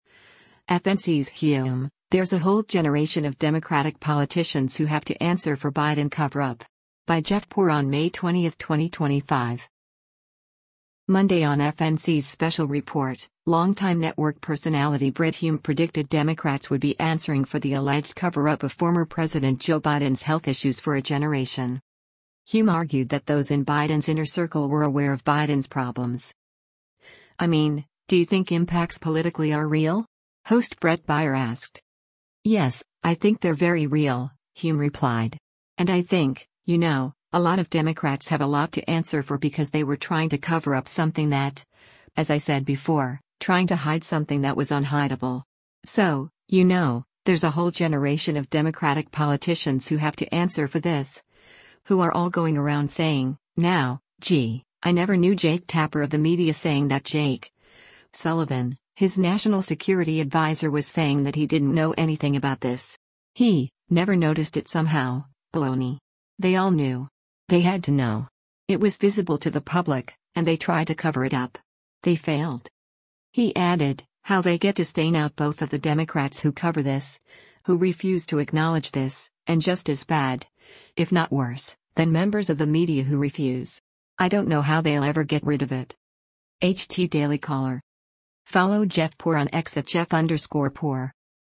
“I mean, do you think impacts politically are real?” host Bret Baier asked.